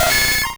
Cri de Nidorino dans Pokémon Rouge et Bleu.